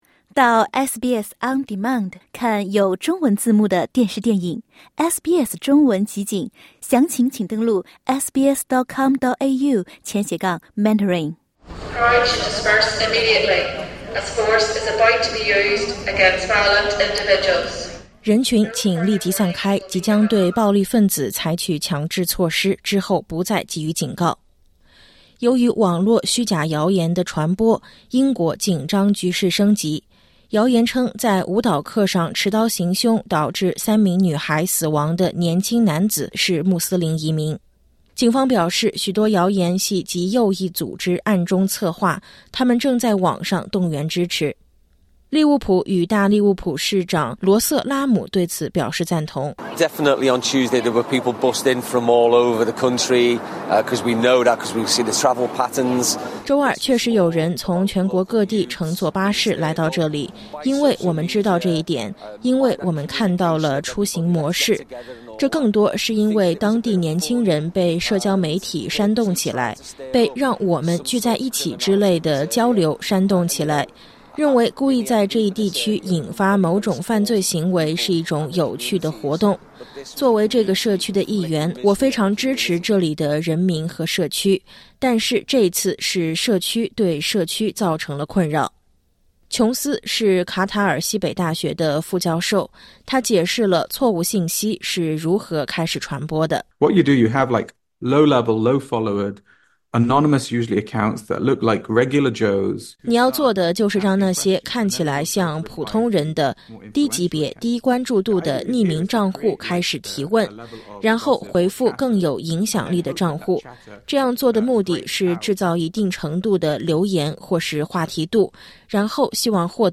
点击音频，收听完整报道。